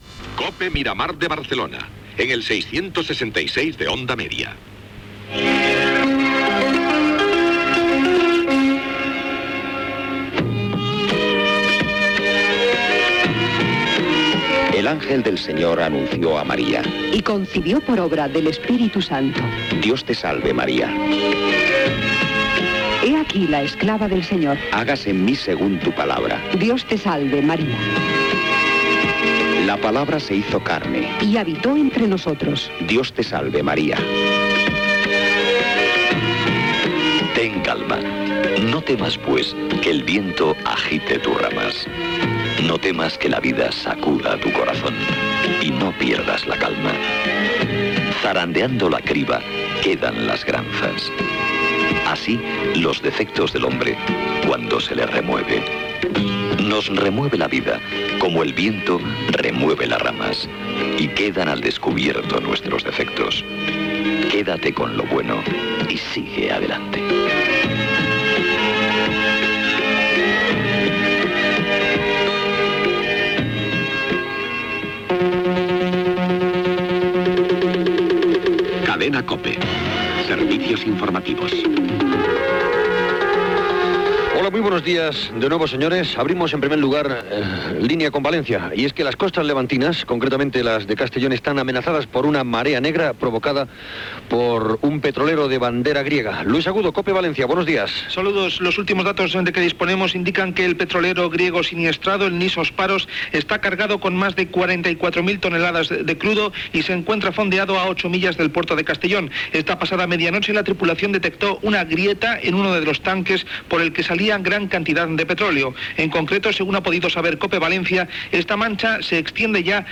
Indicatiu de l'emissora, Hora de l'Àngelus, careta del programa: marea negra davant de Castelló, borsa, Congrés de Diputats i cas Filesa, etc.
Informatiu
FM